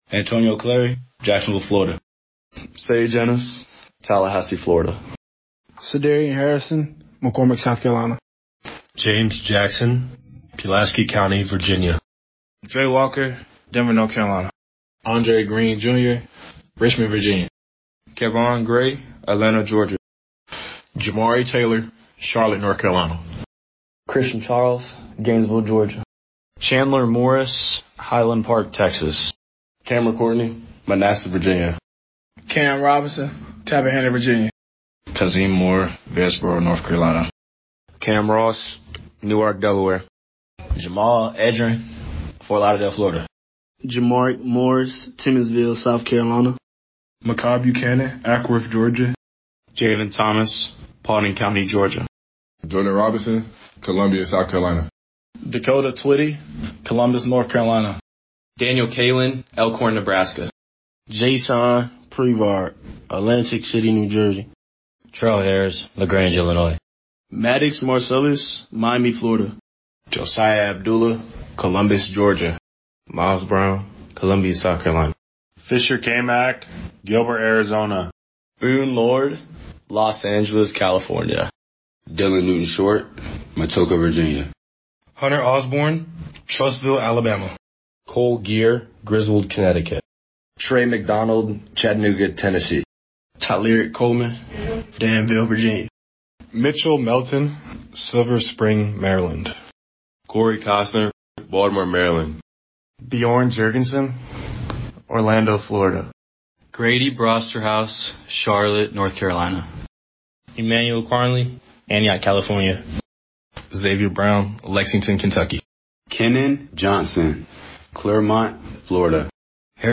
Pronunciations
2025-Team-Pronunciations_Updated.mp3